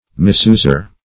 Misuser \Mis*us"er\, n.